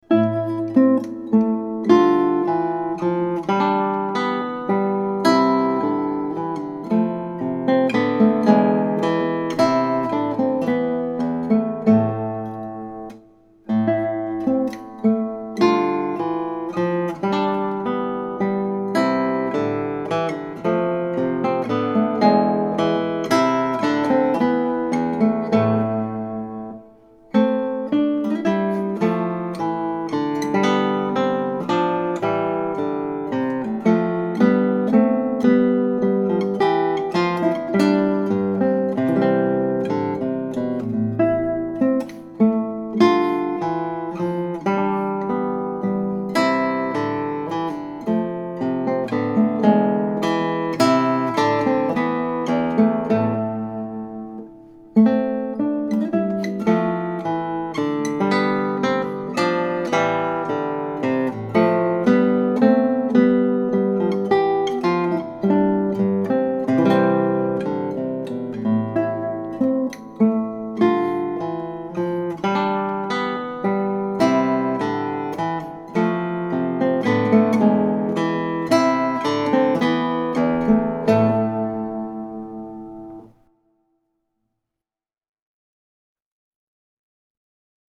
It is elegant and melancholy, and only slightly more difficult than the average solo in this textbook.
The minuet, a stately ballroom dance in triple meter, originated in France and was popular during the seventeenth and eighteenth centuries, particularly among the aristocratic class.
The two voices or melodic lines—soprano and bass—are often locked in a call-and-response texture.
guitar